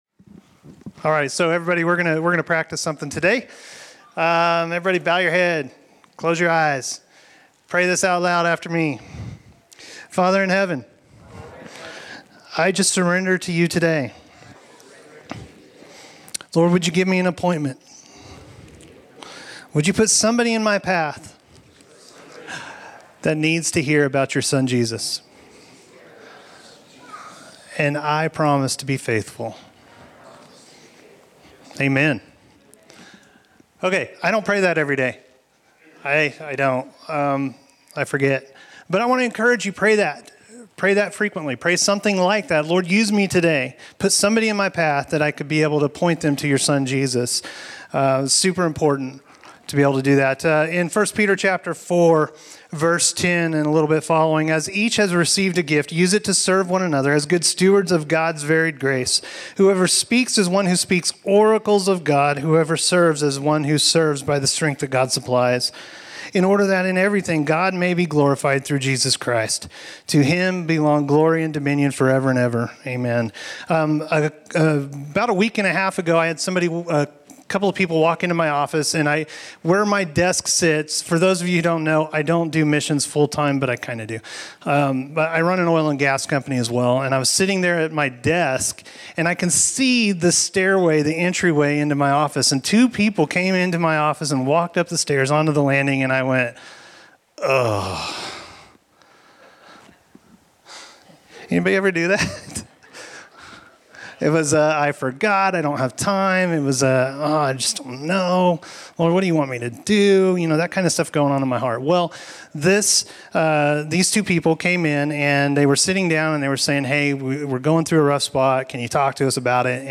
Evangelism Testimony